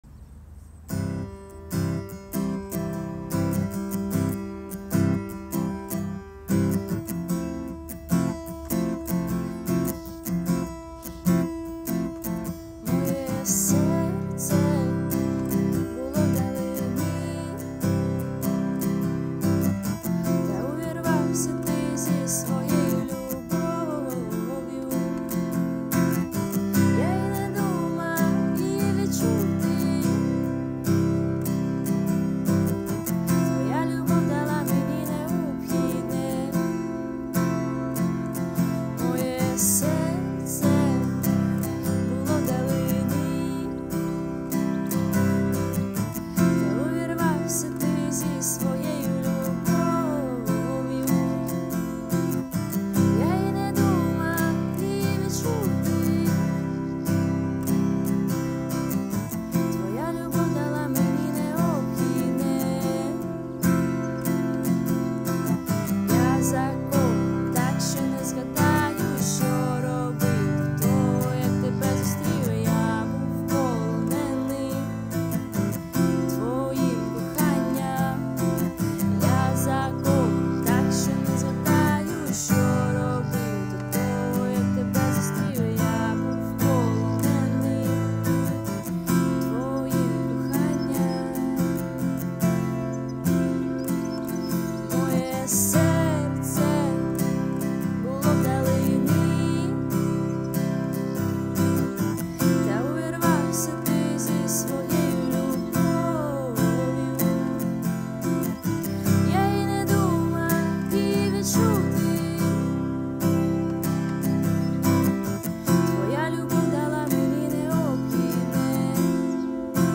127 просмотров 107 прослушиваний 5 скачиваний BPM: 80